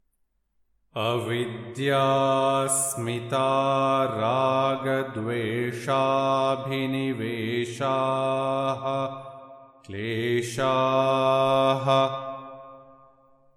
Yoga Sutra 2.3 | Avidhyā'smitā-rāga-dveṣhābhiniveśhāḥ p...| Chant Sutra 2.3
Sutra Chanting